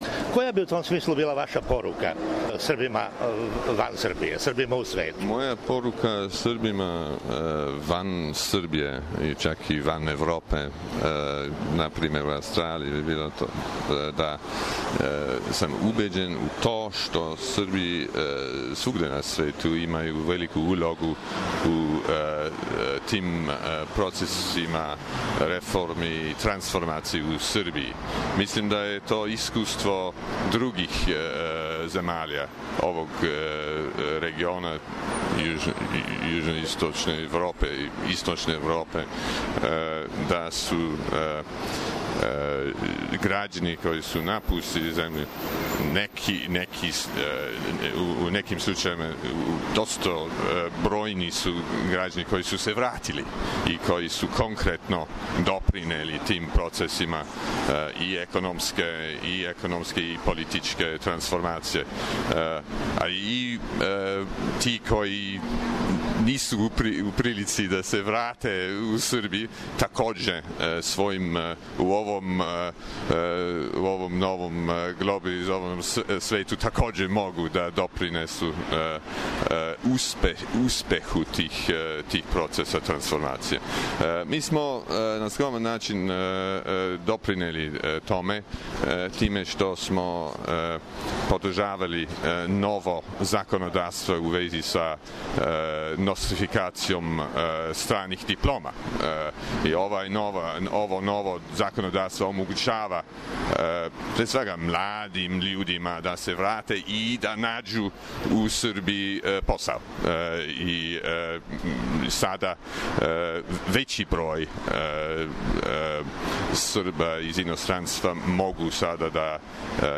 током боравка у "Европском кутку у Нишу"